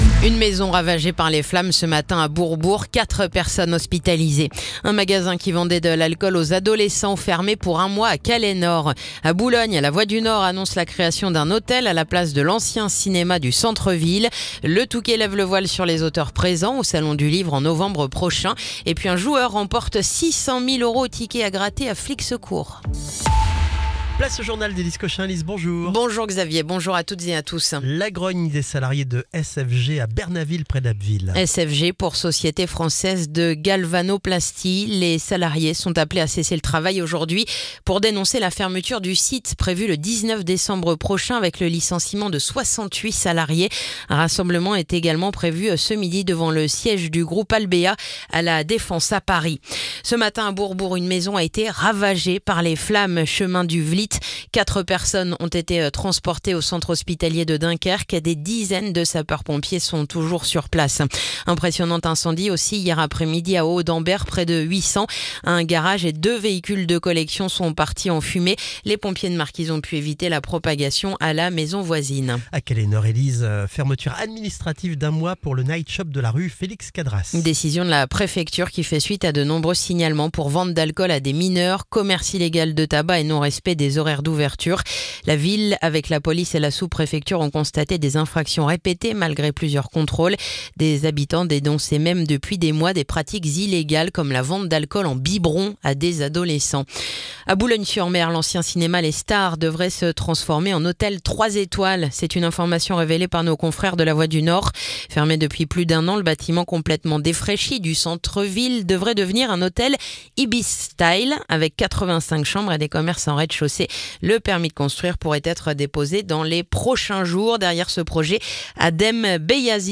Le journal du mercredi 29 octobre